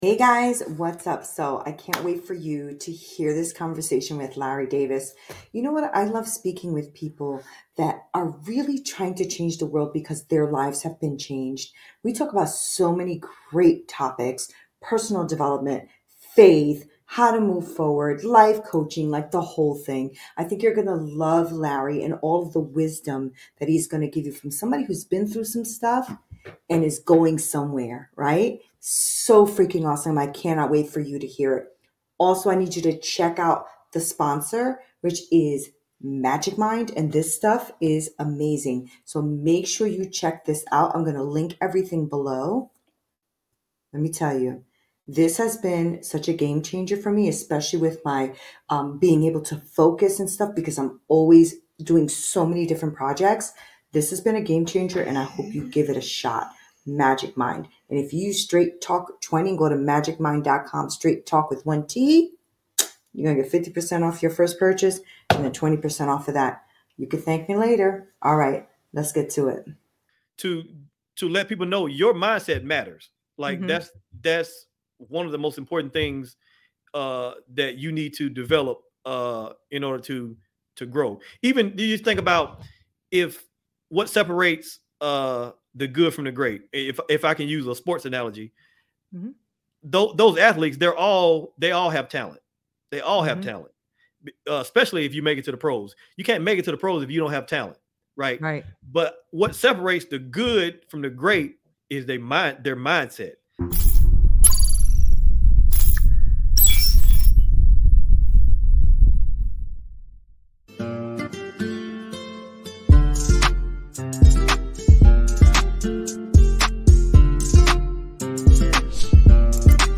Straight Talk No Sugar Added Podcast Ep. 380 Mindset Matters: How To Overcame Failure Apr 01